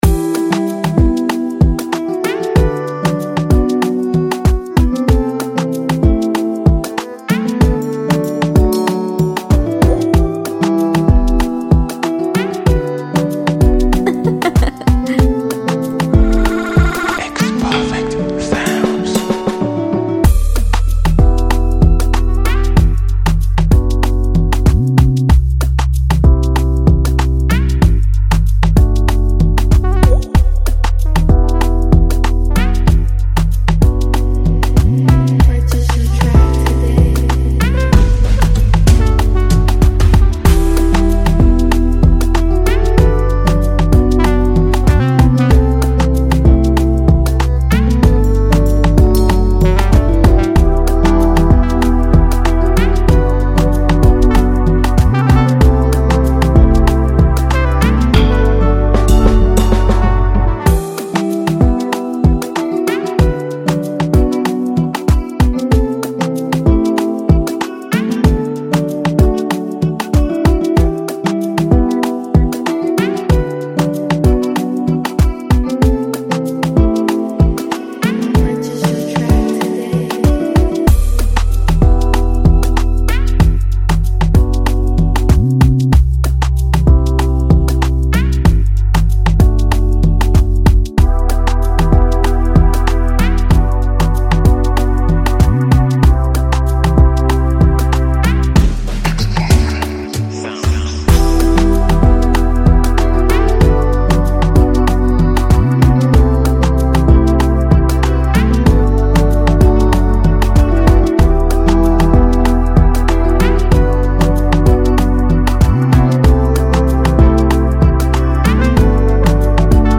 smooth and versatile Afro freebeat instrumental
Afro-fusion instrumental